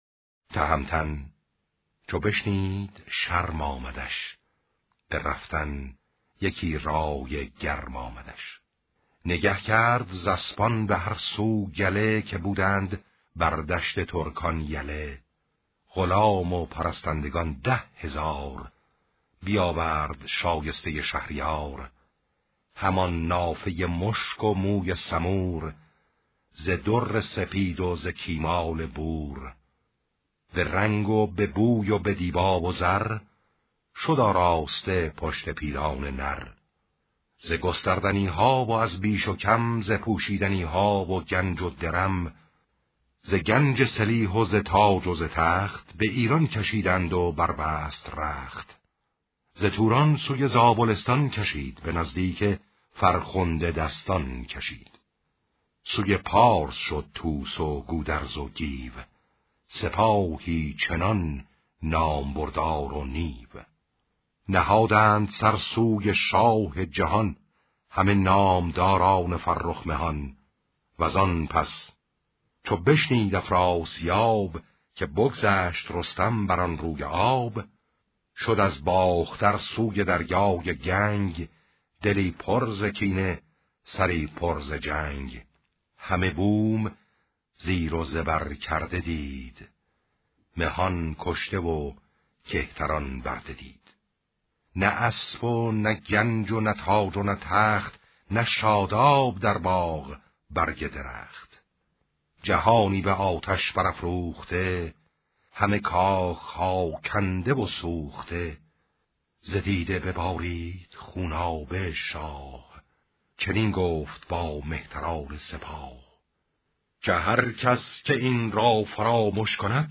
شاهنامه خوانی